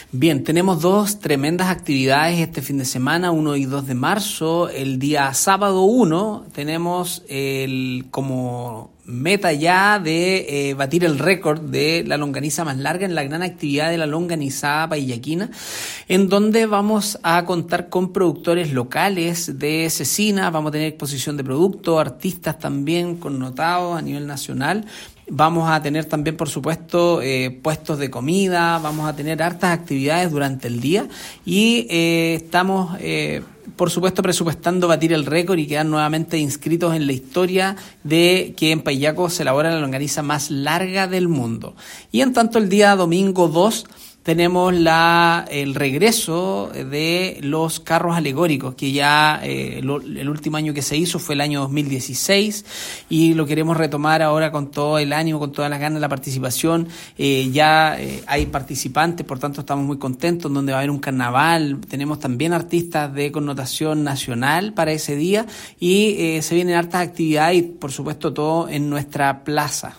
Alcalde-Cristian-Navarrete.mp3